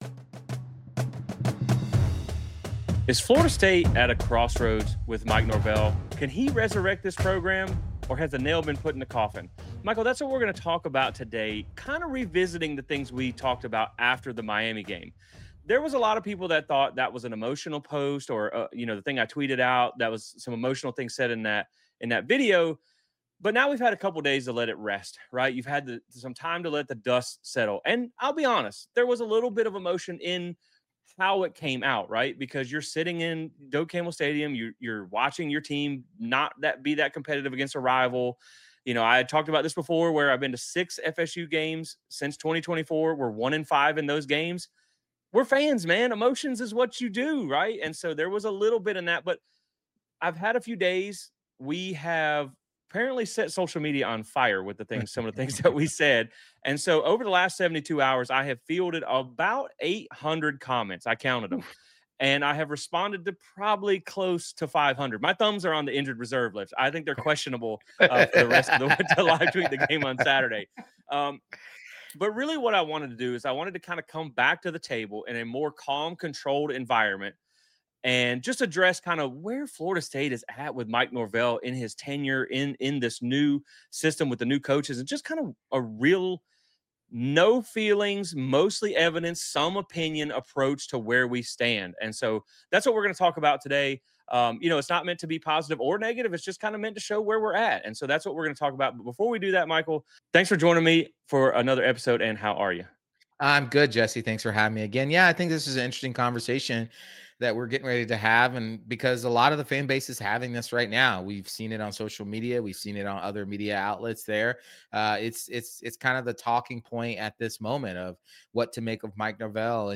In this episode, we have an honest discussion on where FSU football stands with Mike Norvell.